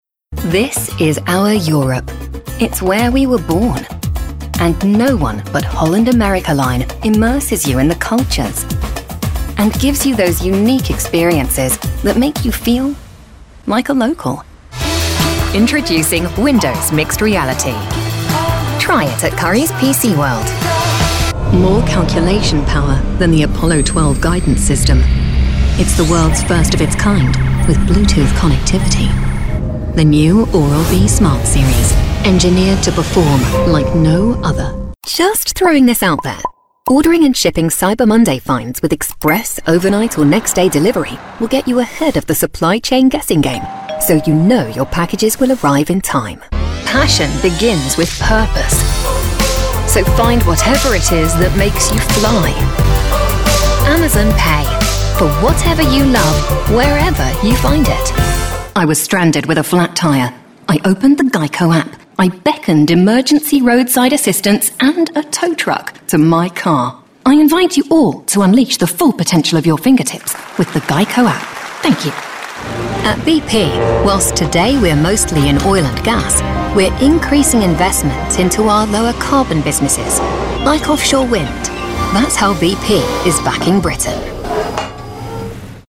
Commercial Reel
accented, accented English, announcer, authoritative, british, classy, confident, cool, Gravitas, professional, promo, retail, sophisticated, worldly